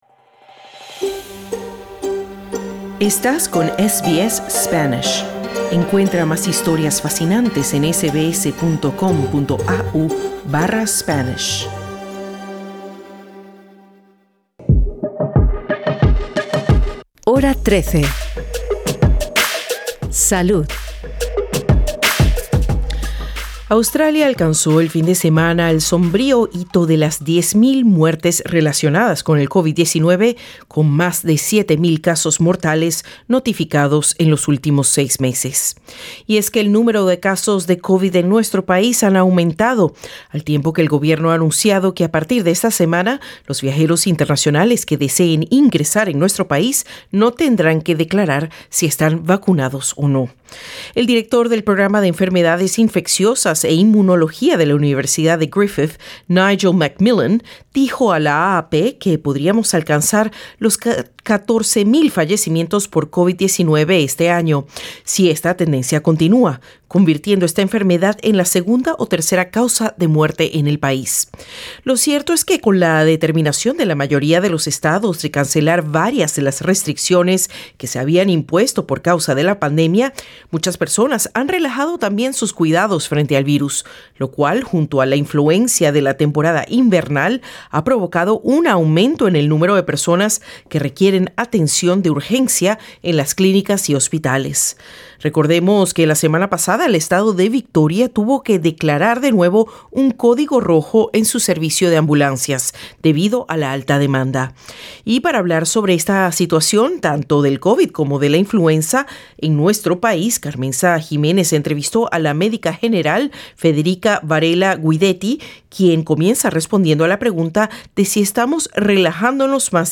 Mientras se advierte a los australianos que se preparen para otra oleada de infecciones por COVID-19 durante el invierno, expertos en salud insisten en que las vacunas y el uso de las mascarillas siguen siendo necesarias para minimizar los estragos del virus. Entrevista